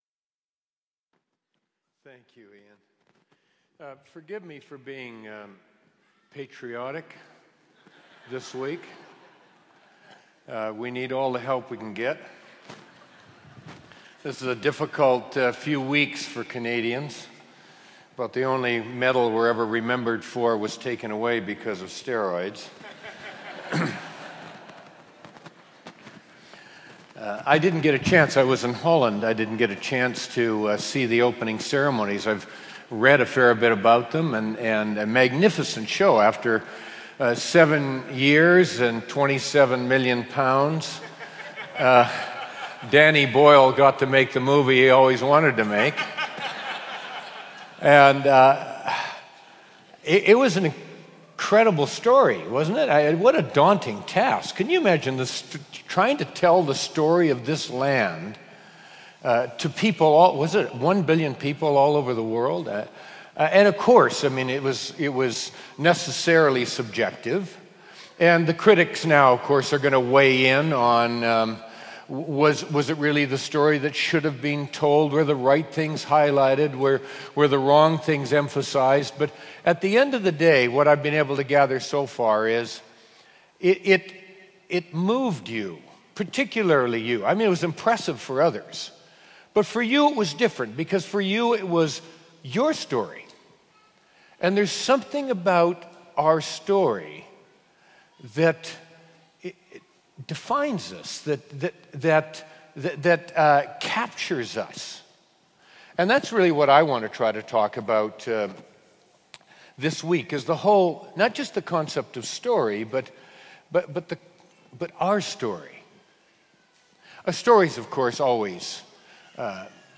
Teaching from New Wine Christian Conference – for all to share.